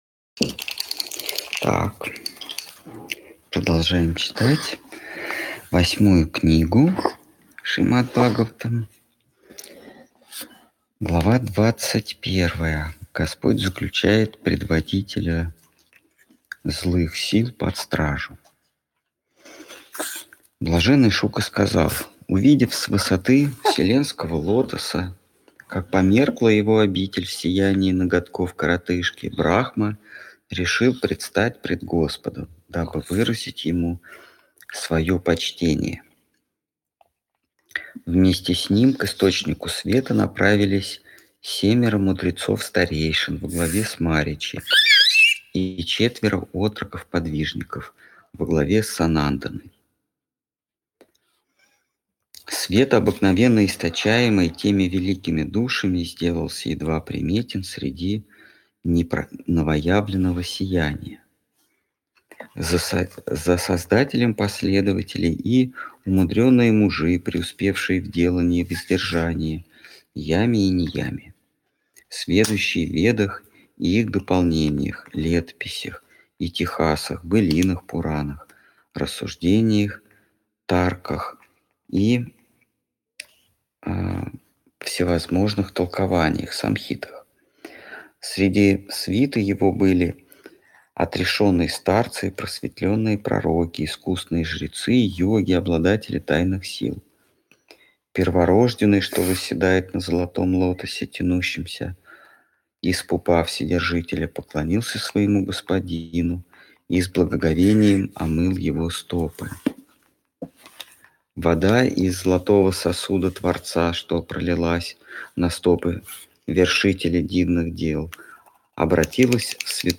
Ответы на вопросы из трансляции в телеграм канале «Колесница Джаганнатха». Тема трансляции: Шримад Бхагаватам.